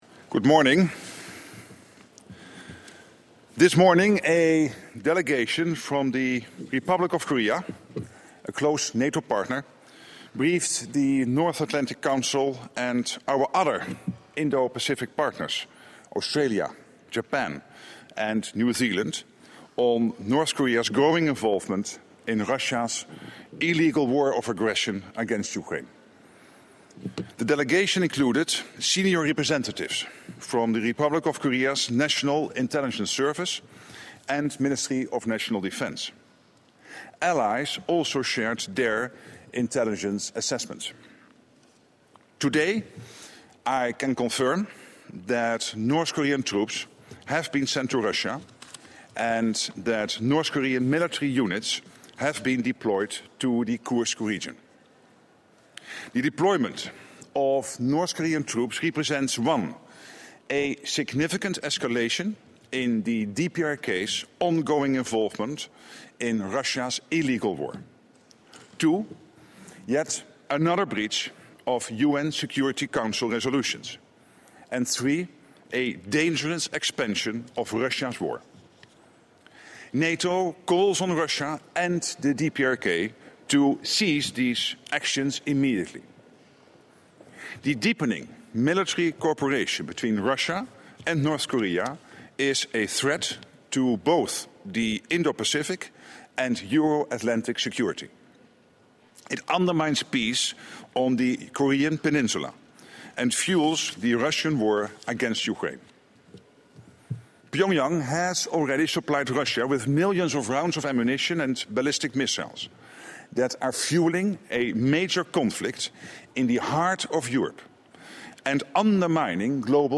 Doorstep statement by NATO Secretary General Mark Rutte
(As delivered)